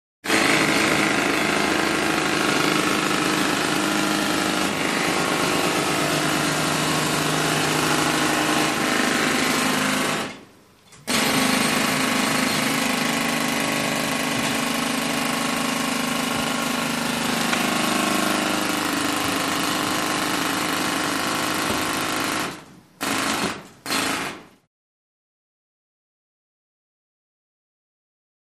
Jackhammer 1; Interior Or Underground, Close Perspective.